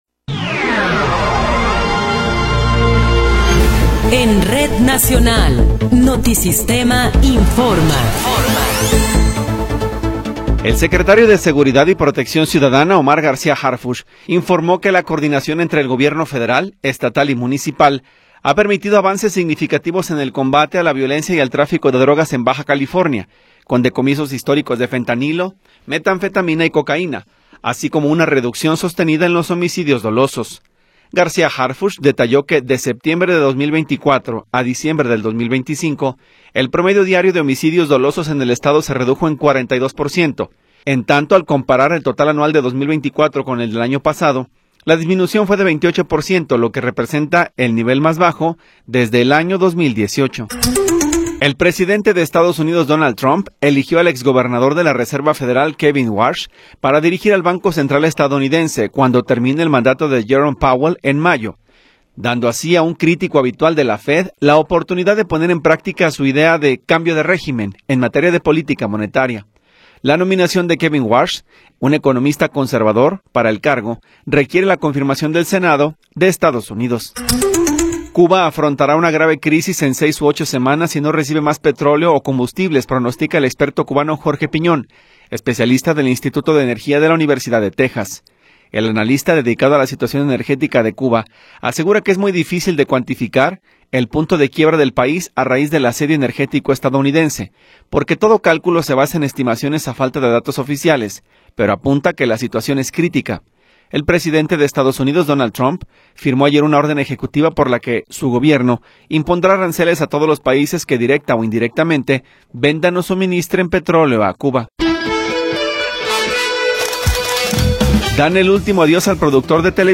Noticiero 11 hrs. – 30 de Enero de 2026
Resumen informativo Notisistema, la mejor y más completa información cada hora en la hora.